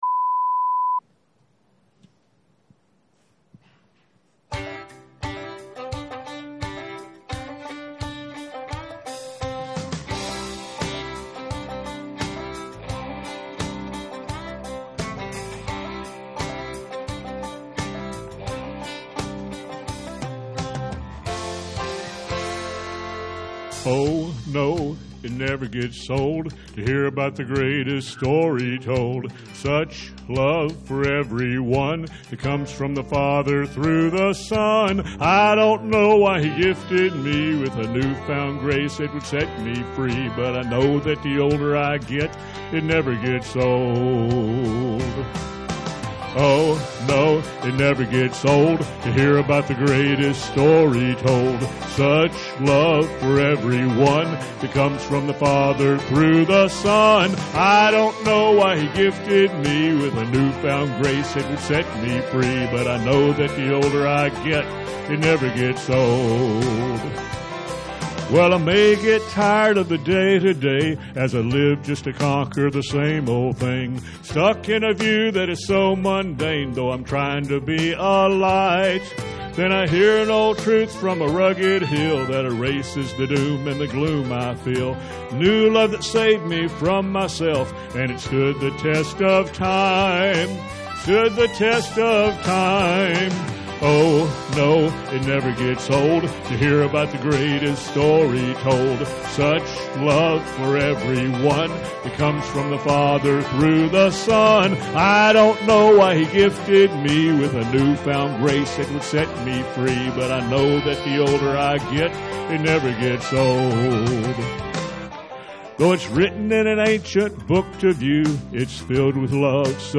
Special Music - Calvary Baptist Church